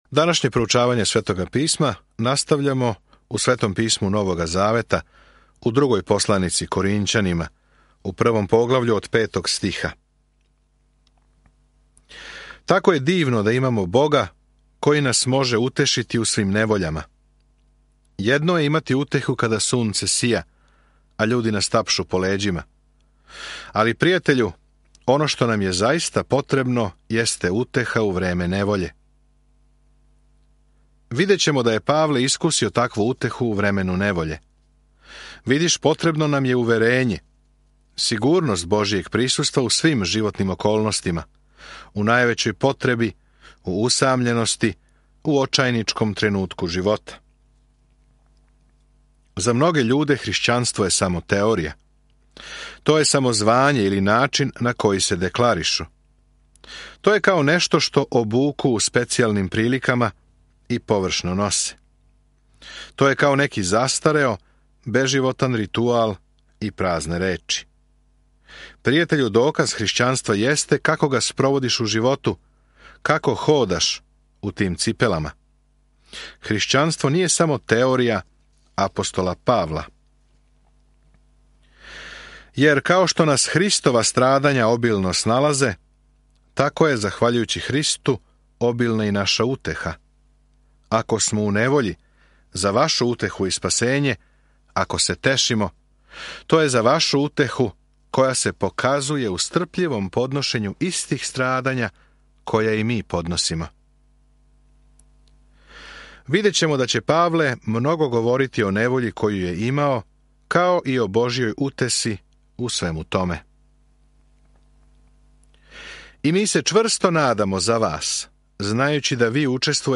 Свакодневно путујте кроз 2 Коринћанима док слушате аудио студију и читате одабране стихове из Божје речи.